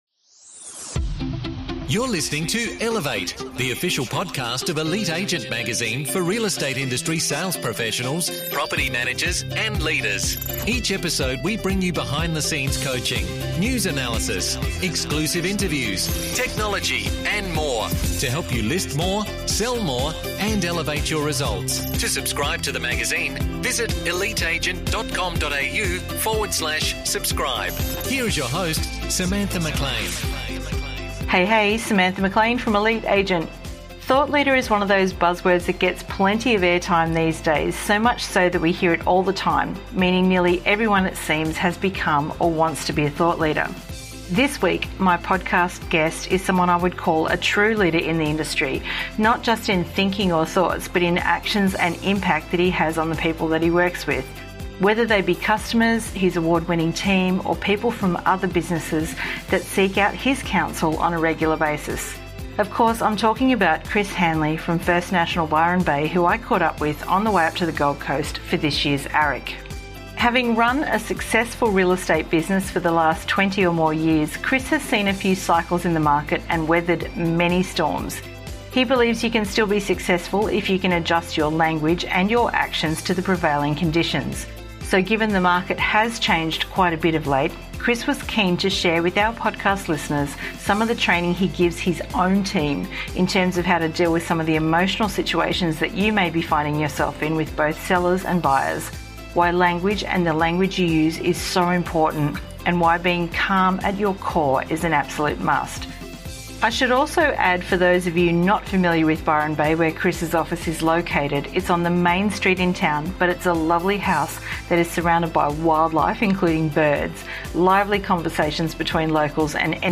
Guest Interview